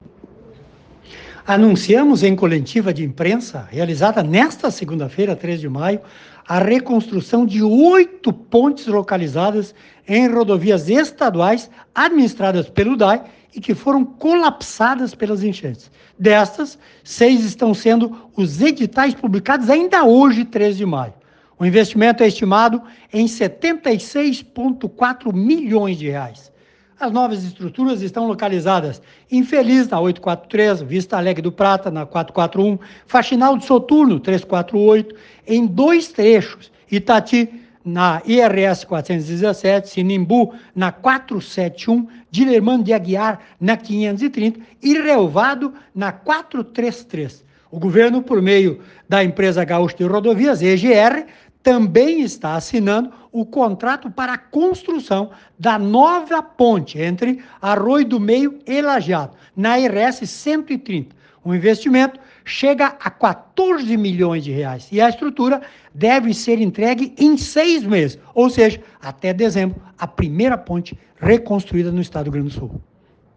Secretário Juvir Costella fala sobre plano de reconstrução de estradas.